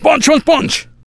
el_primo_atk_03.wav